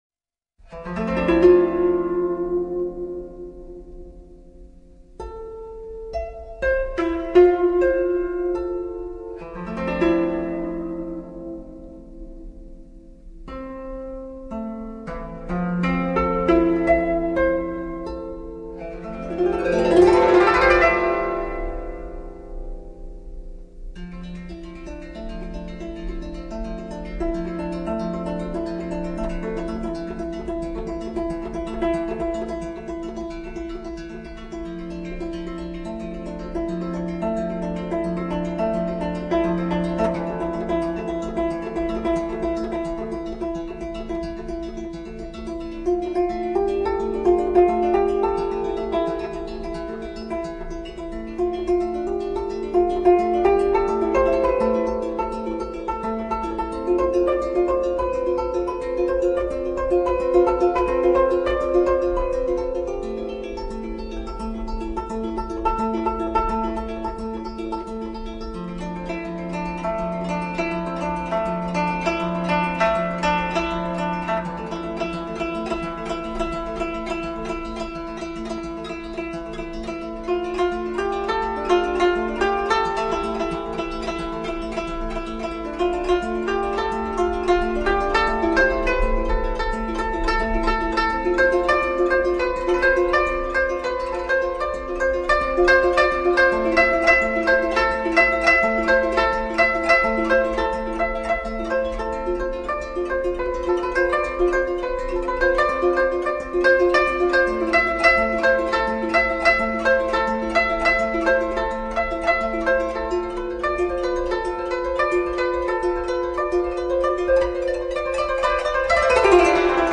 尺八、古筝、吉他